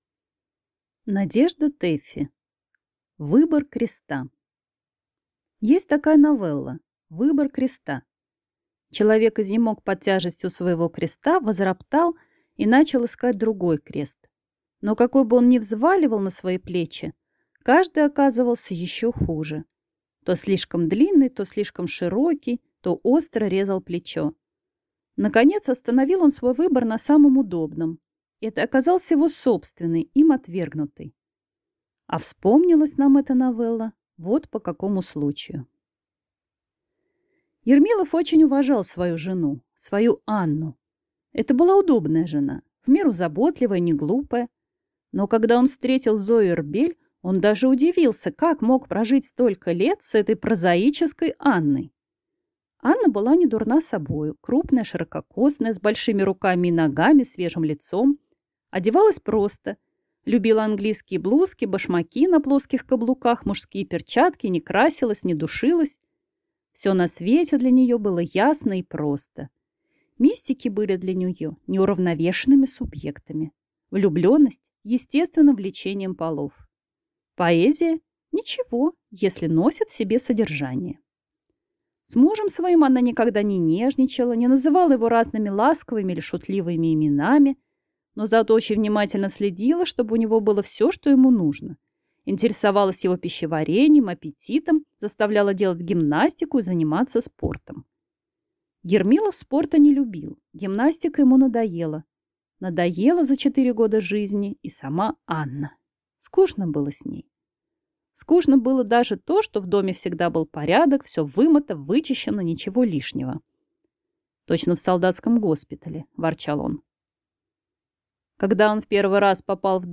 Аудиокнига Выбор креста | Библиотека аудиокниг